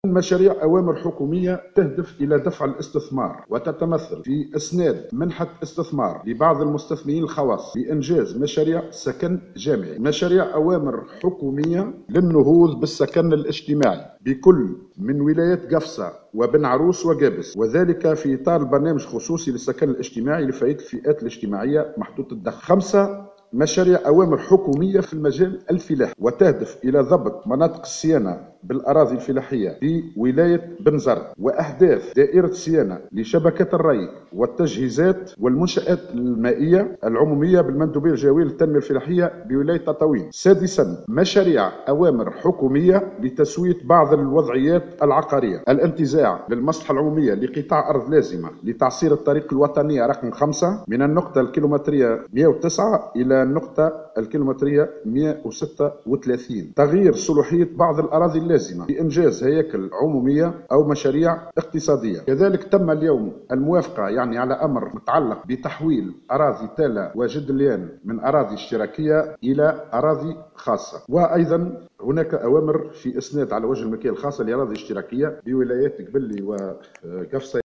وبحسب توضيحات وزير املاك الدولة والشؤون العقارية حاتم العشي في ندوة صحفية فإنه تم النظر في: - مواصلة تنفيذ البرنامج الخاص بتركيز منظومة التصرف في الميزانية حسب الأهداف بما تسمح بحسن توظيف الإمكانيات البشرية والمادية لمختلف الوزارات.